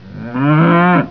This is a collect of cow related sounds and version 2.3 of MooAMP skin free to download:
moo.wav